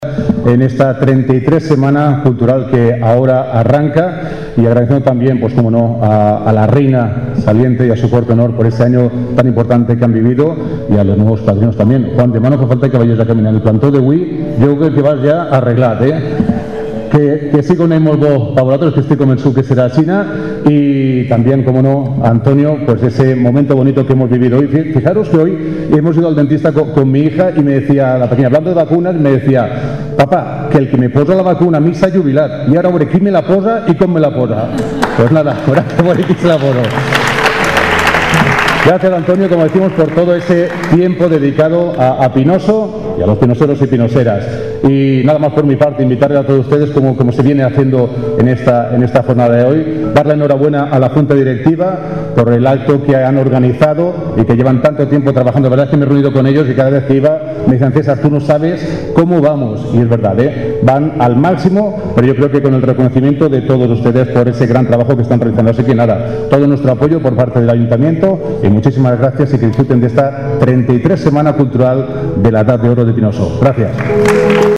La 33ª edición de la Semana Cultural de la Asociación de Jubilados y Pensionistas “11 de septiembre” de Pinoso arrancó este jueves en el auditorio municipal.